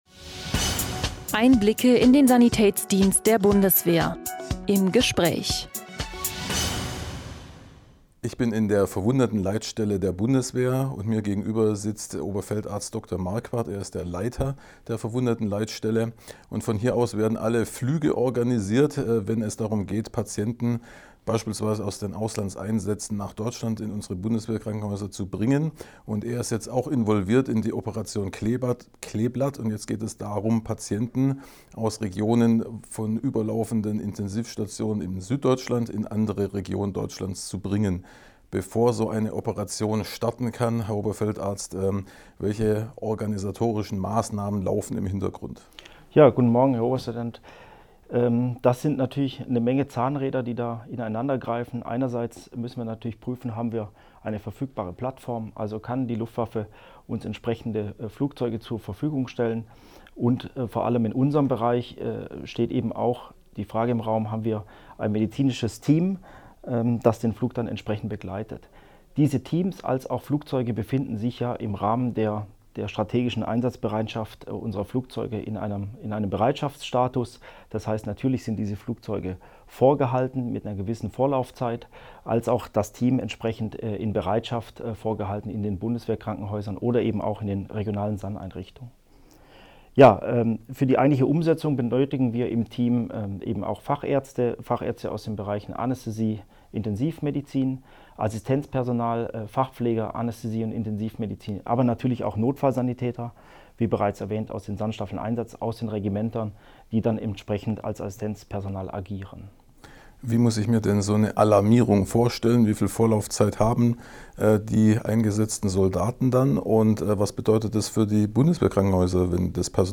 interview-olt-data.mp3